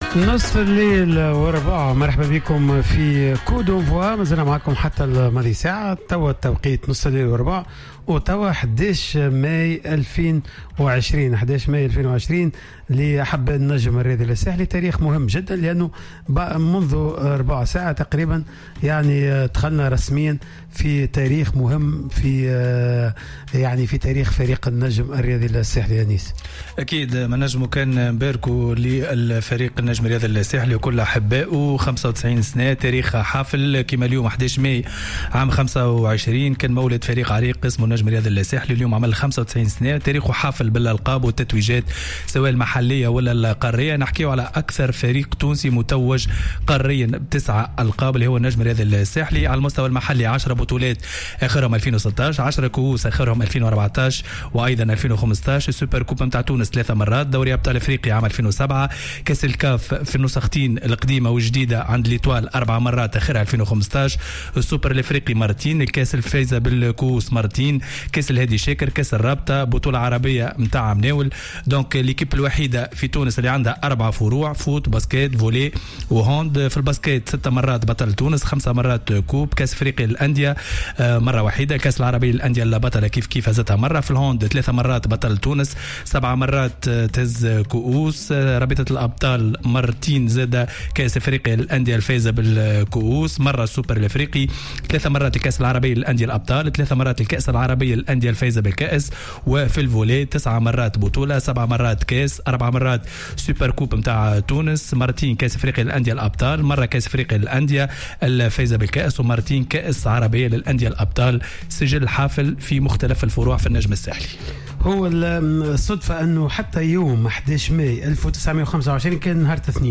تقدم رئيس النجم الساحلي الدكتور رضا شرف الدين خلال تدخله في برنامج coup d'envoi بالتهاني الى جماهير فريقه بمناسبة مرور 95 سنة على تاريخ تأسيس النادي .